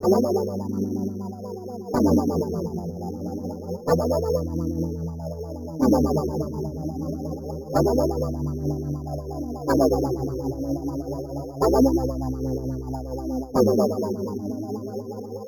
PainPerdu_124_G_dry.wav